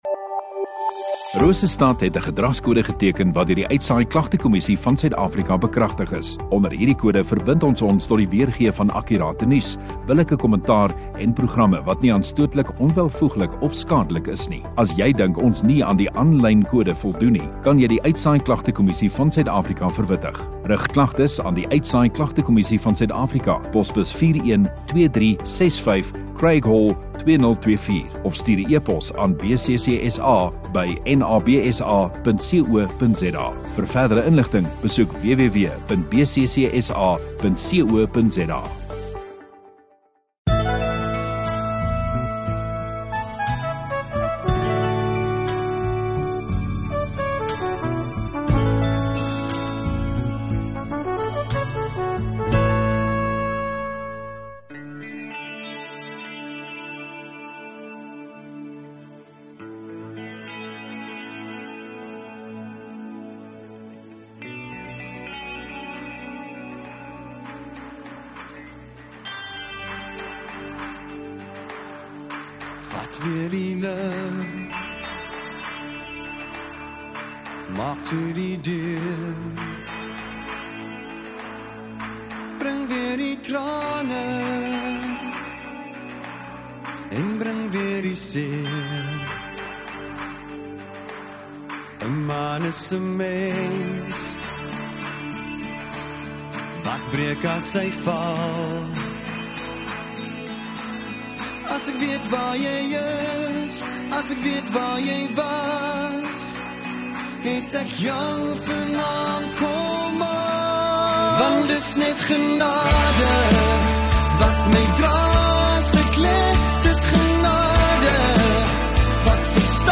6 Oct Sondagoggend Erediens